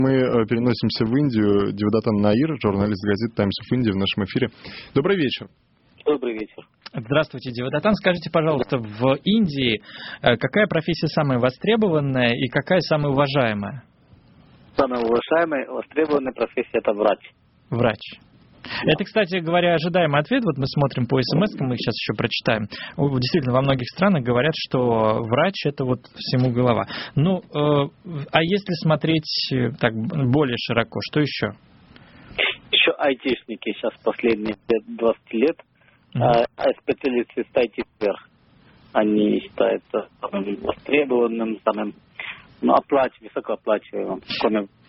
Для подтверждения своих слов приведу отрывки из программы "Популярные и востребованные профессии: опыт разных стран" радиостанции Эхо Москвы.